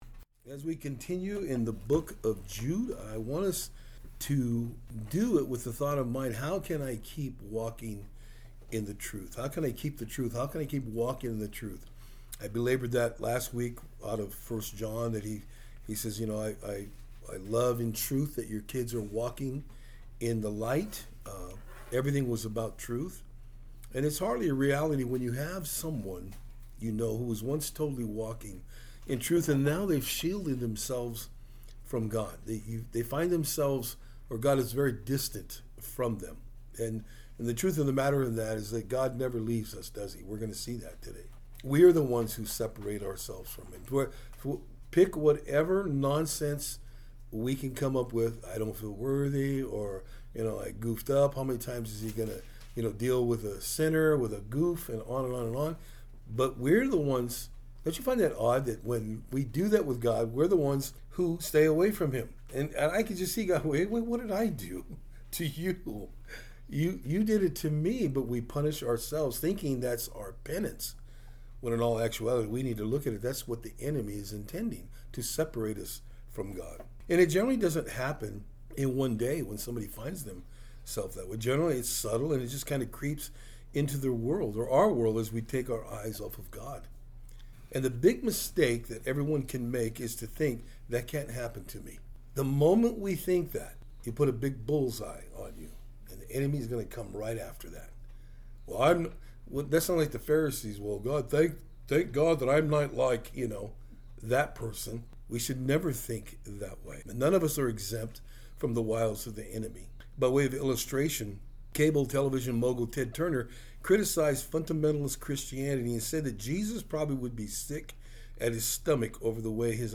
Jude 1-2 Service Type: Thursday Afternoon We delve into the Book Of Jude and look at the first 2 verses.